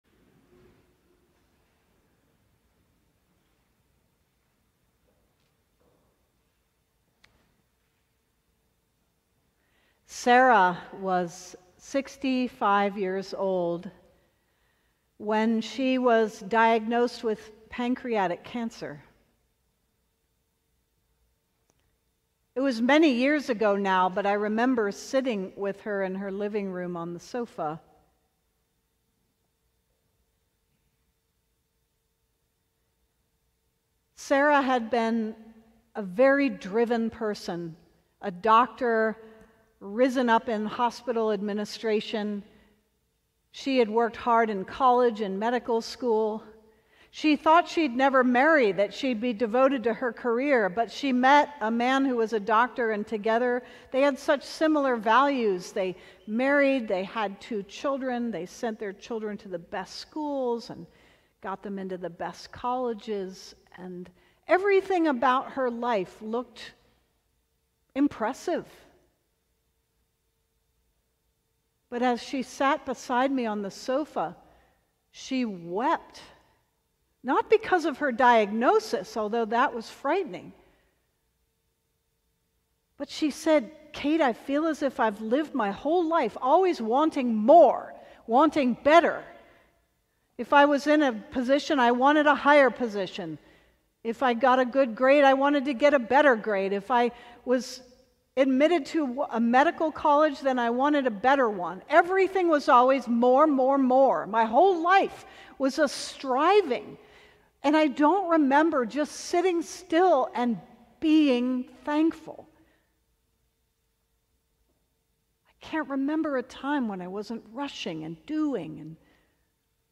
Sermon: More than a Mulberry Tree - St. John's Cathedral